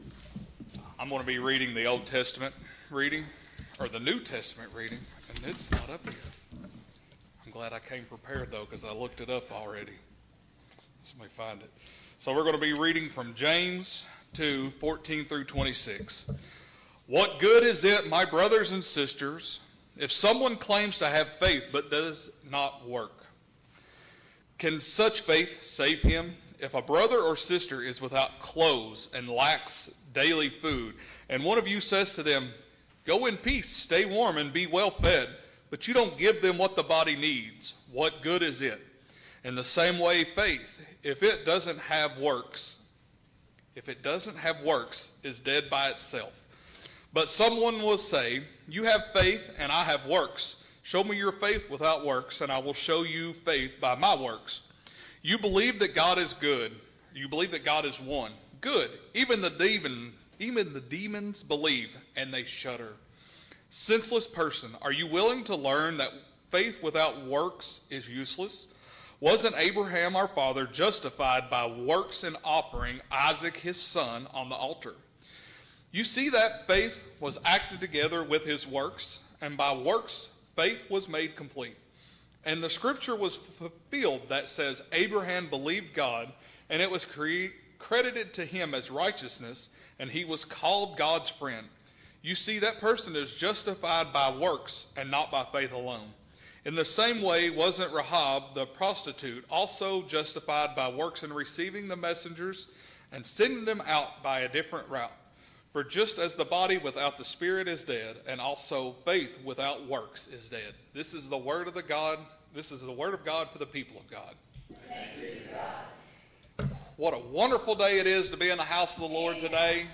Guest Speaker - Hyattsville Baptist Youth